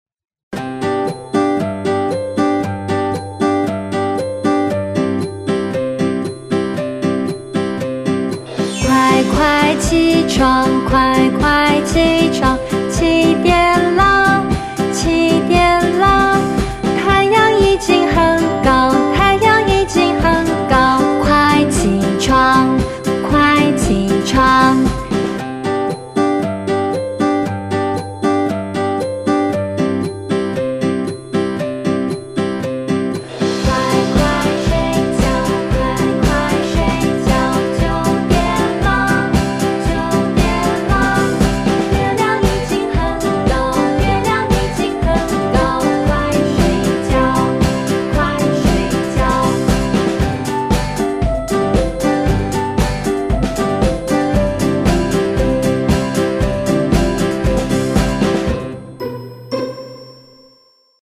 4⃣ Cùng hát nhé.
Sau khi đã học xong kiến thức của bài, chúng mình hãy cùng điểm lại nội dung đã học bằng bài hát này nhé!